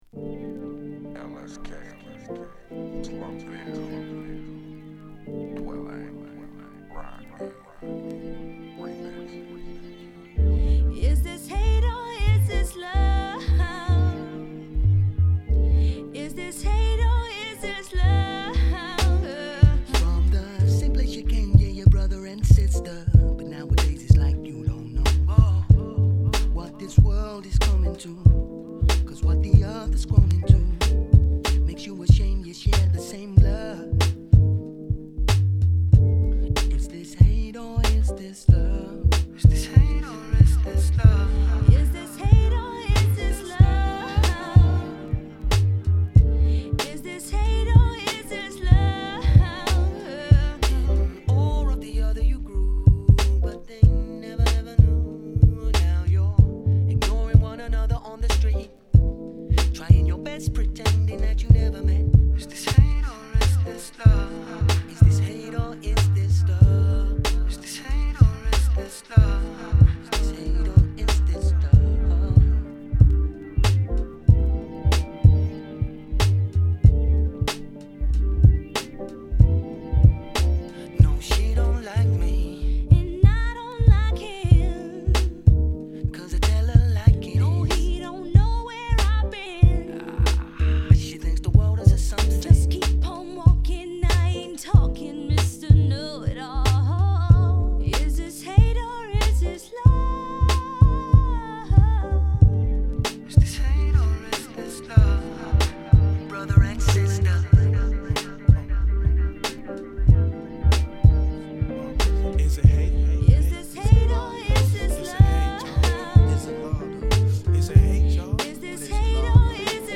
UKを拠点に活動するシンガー／MC
モコったベースに硬質なビートのデトロイトマナー木枯らし系クールトーンビートが沁みるナイスなR&B！